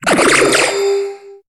Cri de Vémini dans Pokémon HOME.